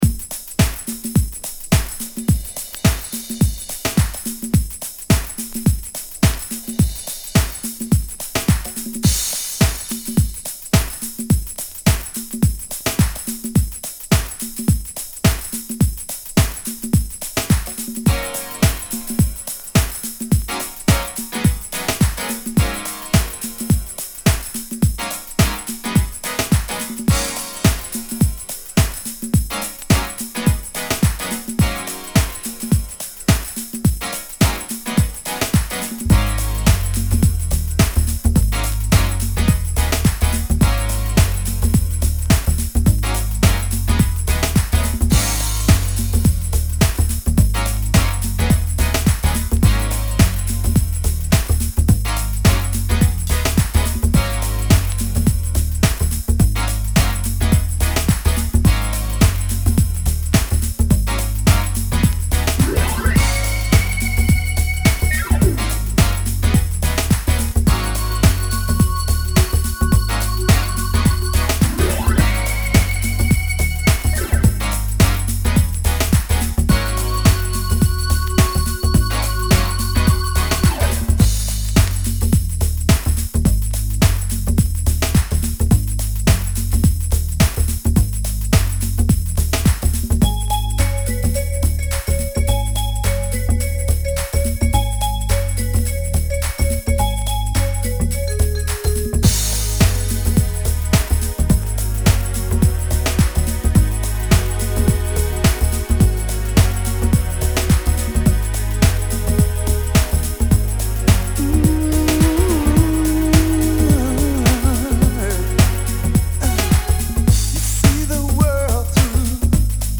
ホーム HOUSE / TECHNO 12' R